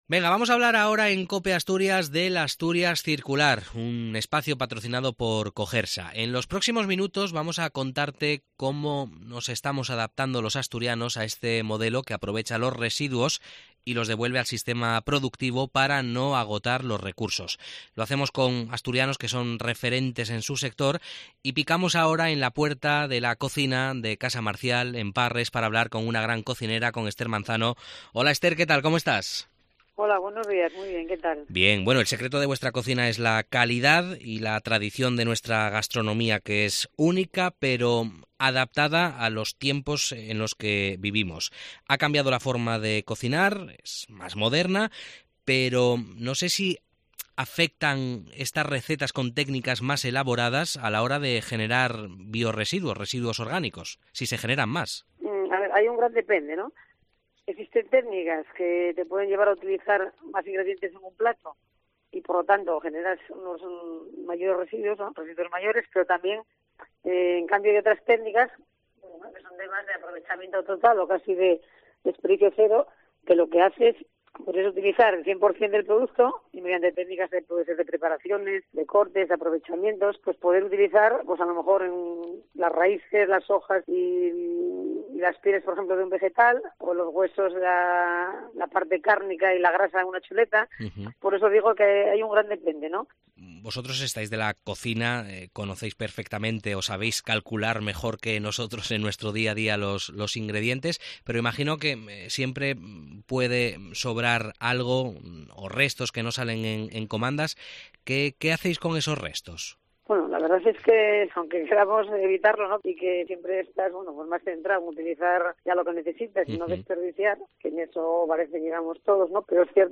Lo hacemos a través de la conversación con asturianos y asturianas que son líderes en sus sectores profesionales (hostelería, deporte, moda, arte, cultura, ciencia...) y que, además, enarbolan un compromiso claro con el medio ambiente y la sosteniblidad.